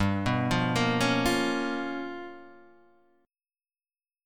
G 7th Sharp 9th Flat 5th